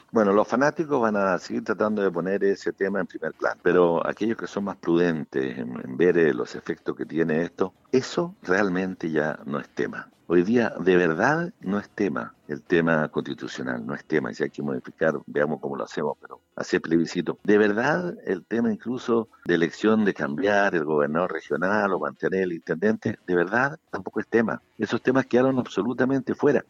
En entrevista con Radio Sago, el Intendente de la región de Los Lagos se refirió a la situación actual que vive el país en torno al coronavirus y los efectos que esta pandemia podría traer consigo en el futuro.